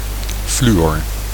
Ääntäminen
Synonyymit difluor gaz fluor Ääntäminen France Tuntematon aksentti: IPA: /fly.ɔʁ/ Haettu sana löytyi näillä lähdekielillä: ranska Käännös Konteksti Ääninäyte Substantiivit 1. fluor {n} kemia Suku: m .